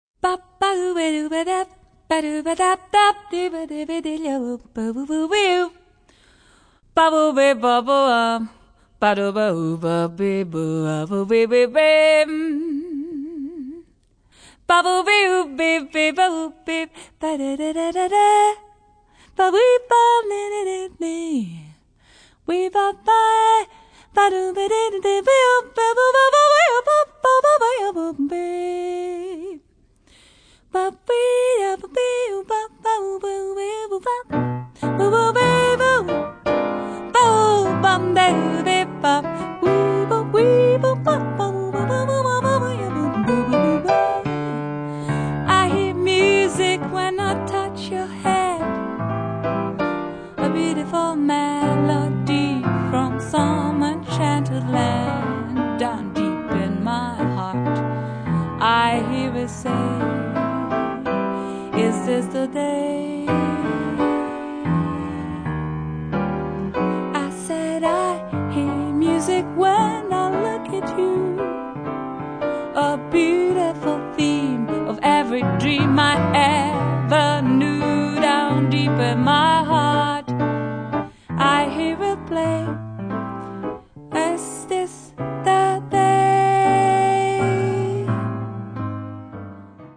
La bella e morbida voce